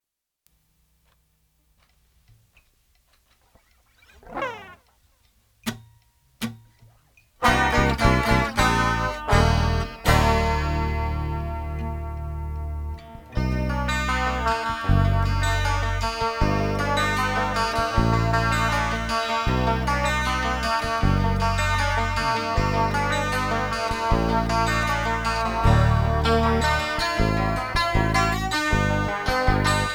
Скачать припев
2025-10-03 Жанр: Альтернатива Длительность